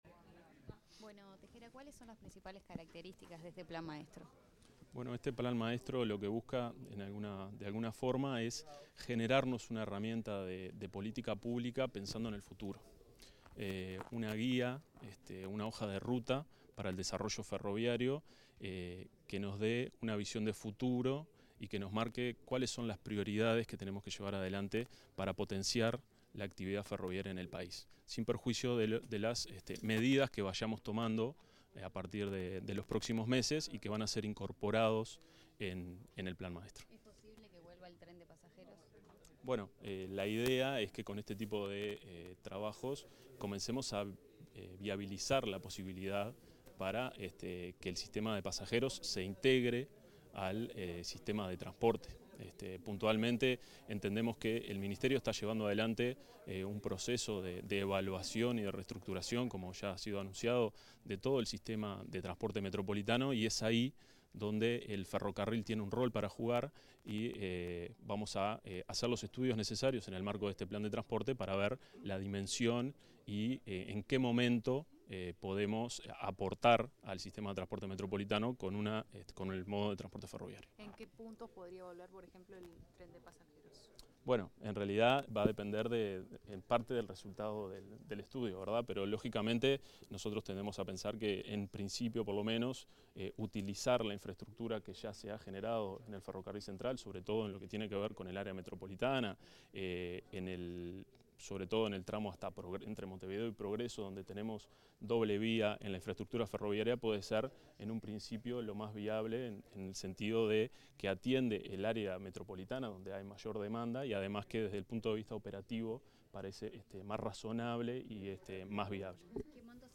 Declaraciones del director nacional de Transporte Ferroviario, Waverley Tejera
Declaraciones del director nacional de Transporte Ferroviario, Waverley Tejera 28/05/2025 Compartir Facebook X Copiar enlace WhatsApp LinkedIn Tras participar en la firma de un convenio para el desarrollo del Plan Maestro de Transporte Ferroviario, el director nacional de Transporte Ferroviario, Waverley Tejera, realizó declaraciones a la prensa.